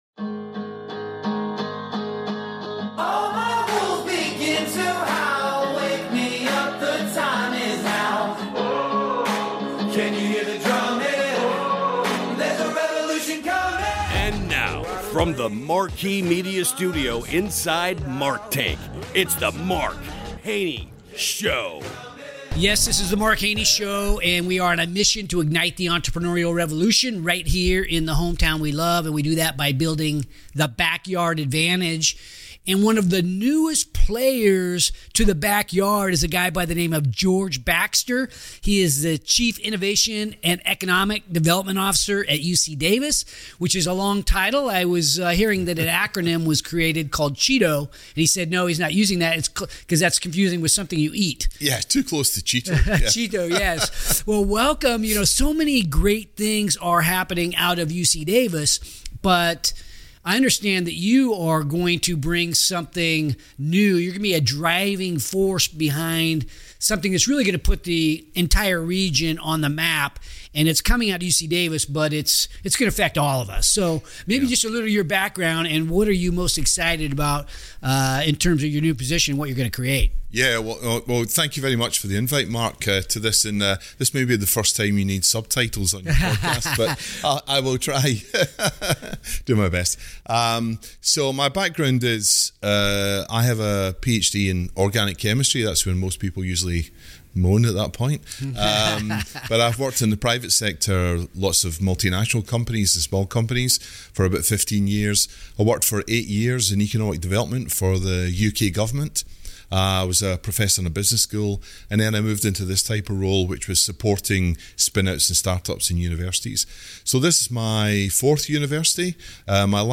Don’t miss this fascinating discussion!